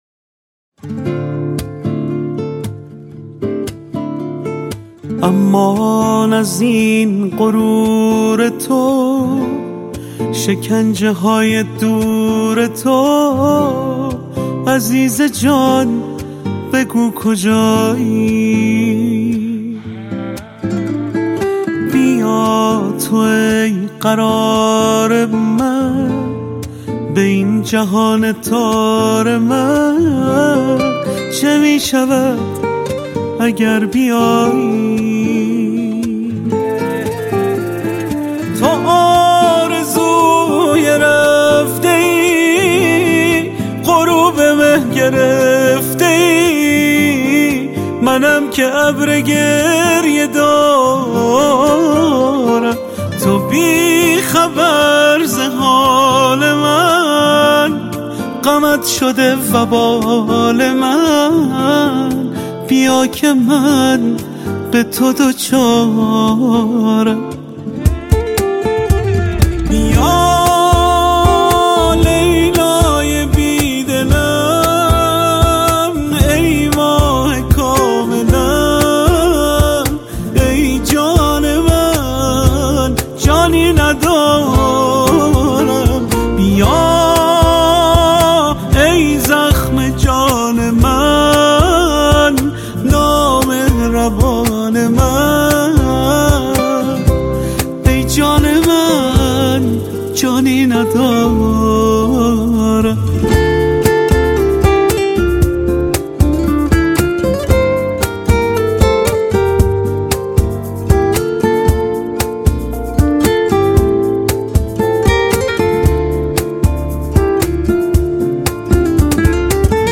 دانلود آهنگ غمگین جدید